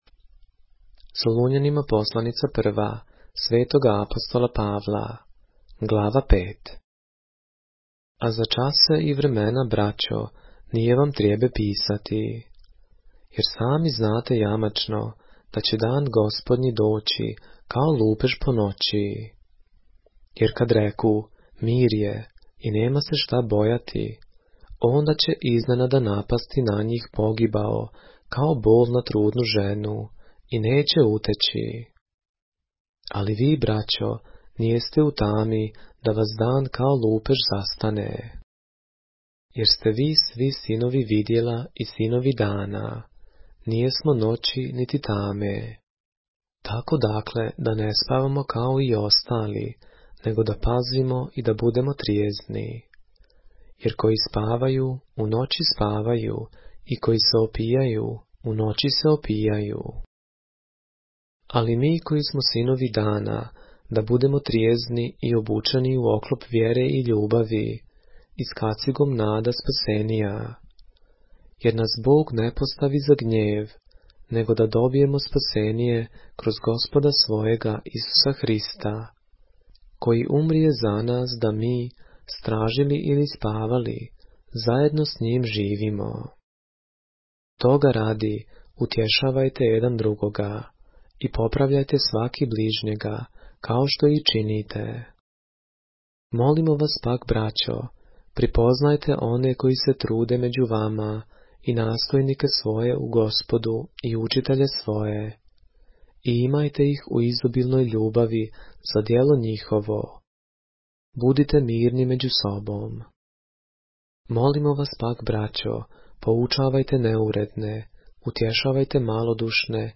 поглавље српске Библије - са аудио нарације - 1 Thessalonians, chapter 5 of the Holy Bible in the Serbian language